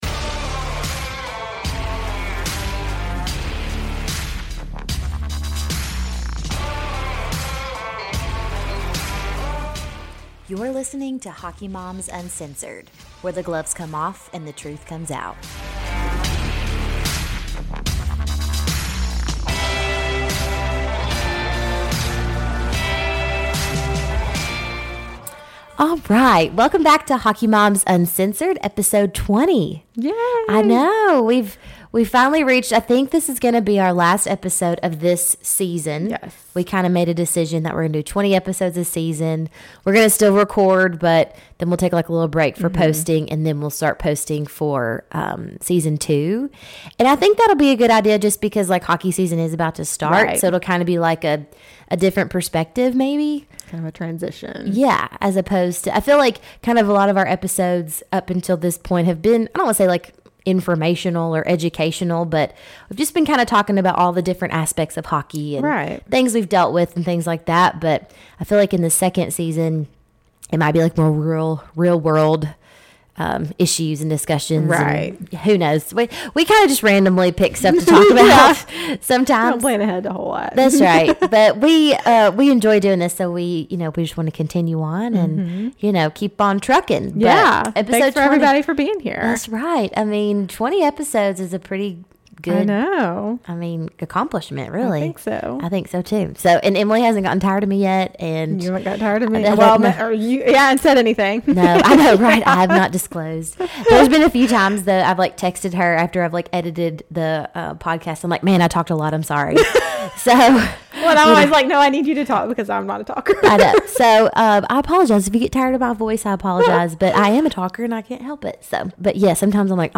Come chill with 2 southern hockey moms ready to spill the tea on the wild and wonderful world of youth hockey. Join us each week as we navigate the highs and lows of youth hockey in the South.